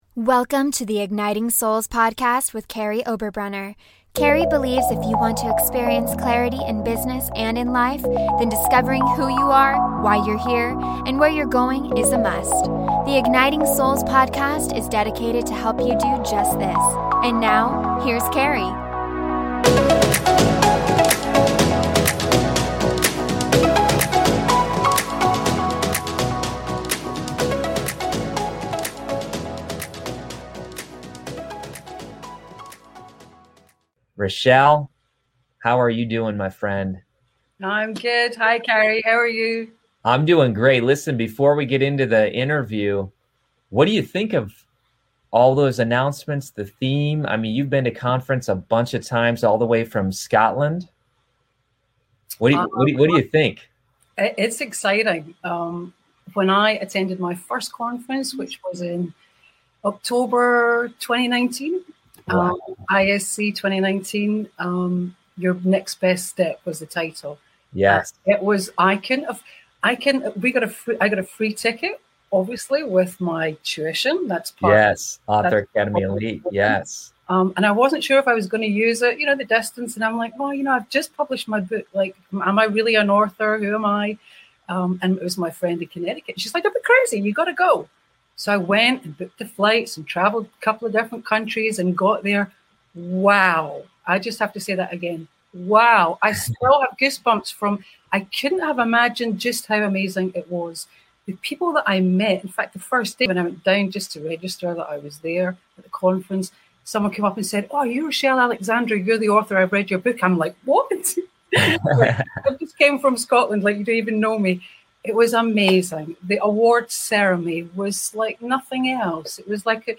Find out some of the secrets to her success, why she wrote this book and what her plans are for the future in this interview I did with her as part of our seven-year Author Academy Elite Celebration.